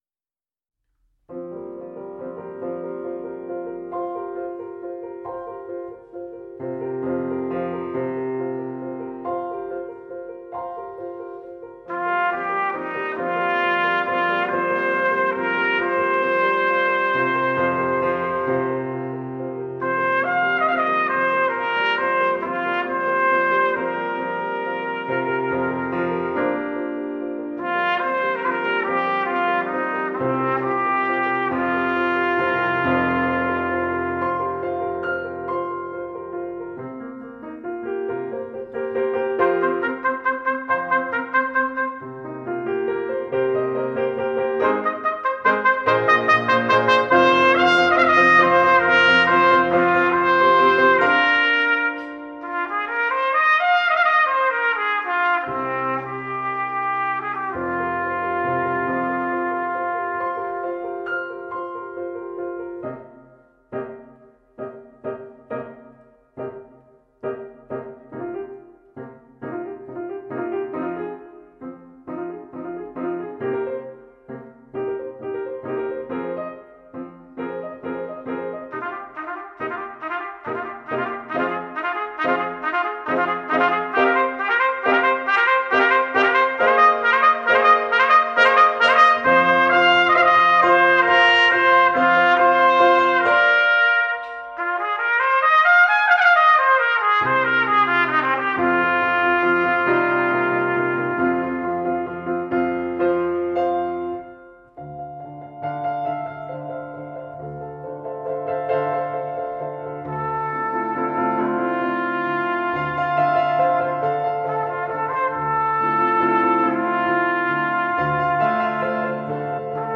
for Trumpet and String Orchestra (2008)
trumpet
piano
The ending is quiet, fading away.